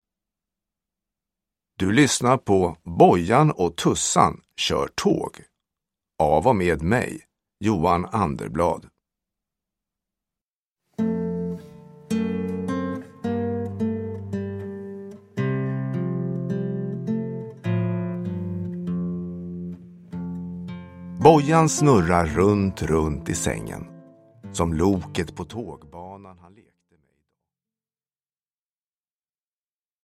Bojan och Tussan kör tåg – Ljudbok